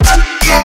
• Качество: 331, Stereo
громкие
Electronica
Короткий звук на смс-ку - самое то